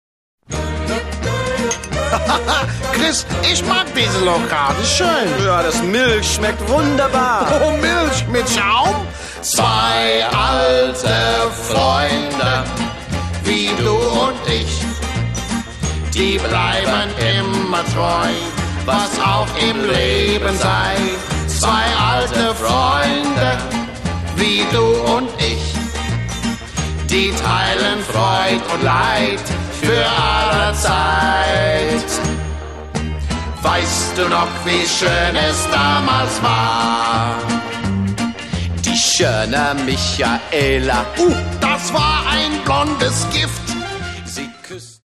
Jazzsänger, Entertainer, Schlager-Veteran
Jazz, Swing, Funk, Schlager
Aufgenommen 1962 - 1966